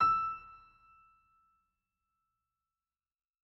SoftPiano
e5.mp3